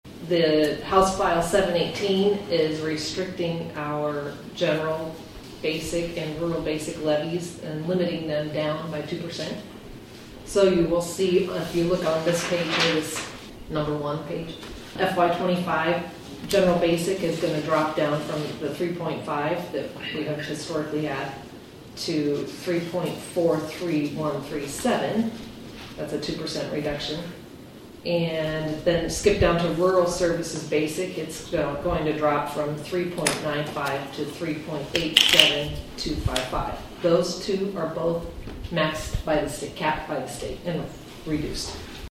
Auditor Kathy Somers said the budget is not complete yet, but she gave an update on what the levies are currently looking like.